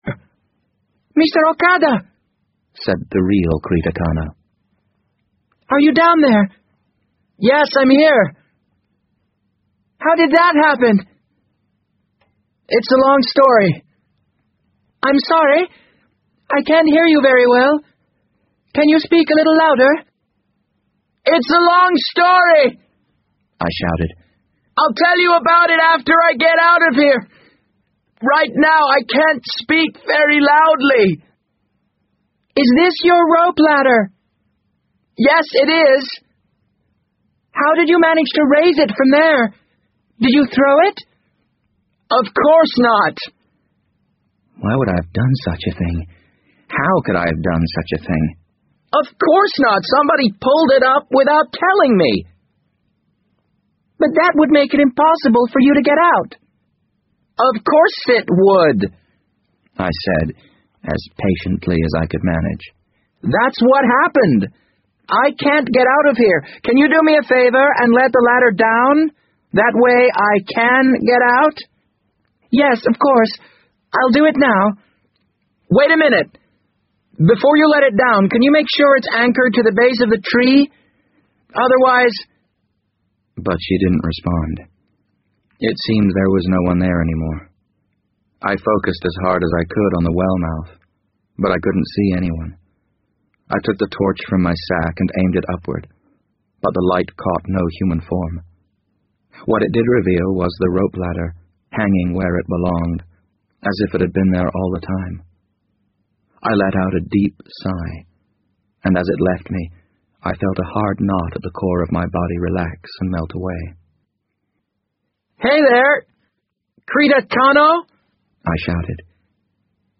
BBC英文广播剧在线听 The Wind Up Bird 007 - 13 听力文件下载—在线英语听力室